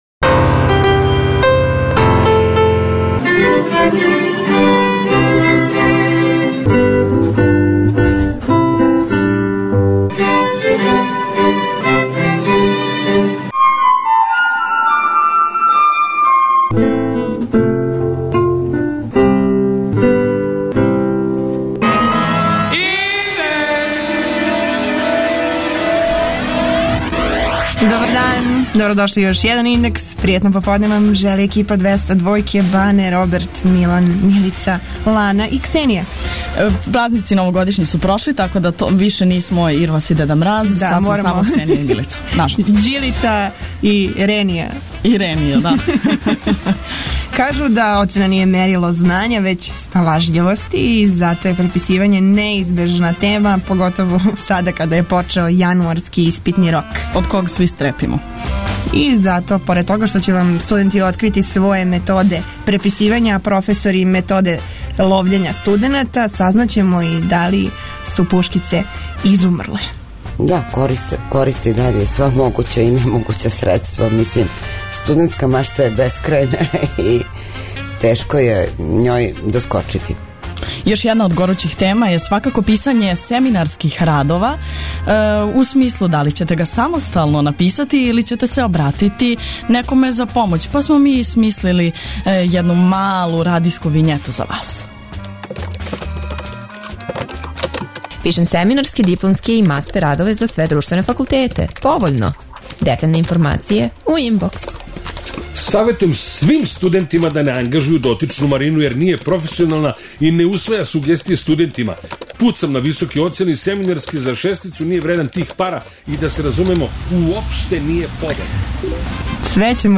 Студенти ће вам открити своје методе преписивања, а професори методе "ловљења" студената. Причамо и о продаји семинарских и других радова, као и о казнама.